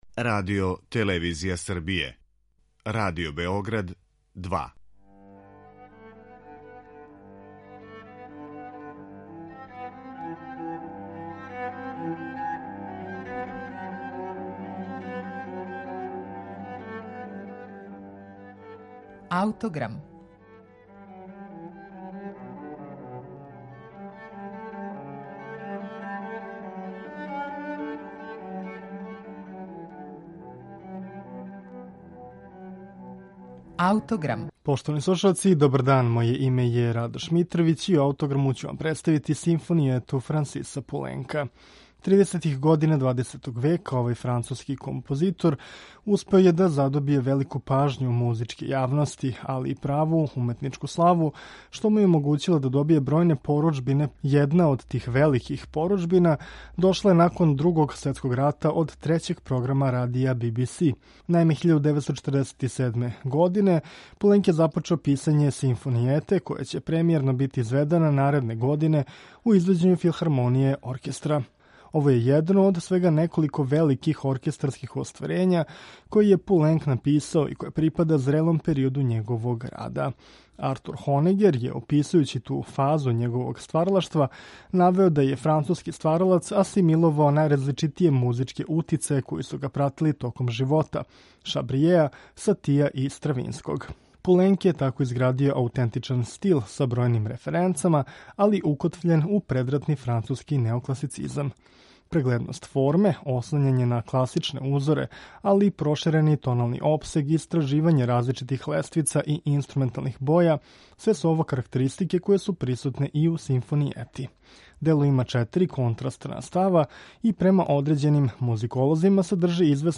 Ми ћемо је слушати у интерпретацији Париског оркестра, под управом Жоржа Претреа.